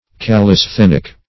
Search Result for " callisthenic" : The Collaborative International Dictionary of English v.0.48: Callisthenic \Cal`lis*then"ic\, a., Callisthenics \Cal`lis*then"ics\, n. See Calisthenic , Calisthenics .